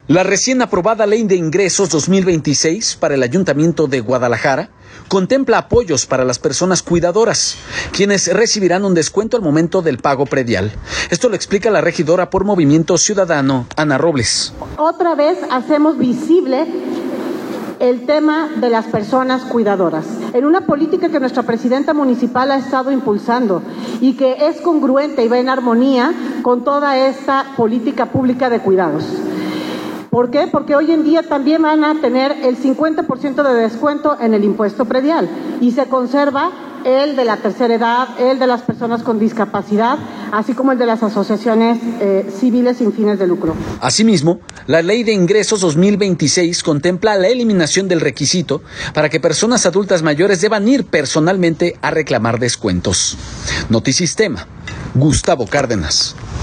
La recién aprobada Ley de Ingresos 2026 para el Ayuntamiento de Guadalajara contempla apoyos para las personas cuidadoras, quienes recibirán un descuento al momento del pago predial. Esto lo explica la regidora por Movimiento Ciudadano, Ana Robles.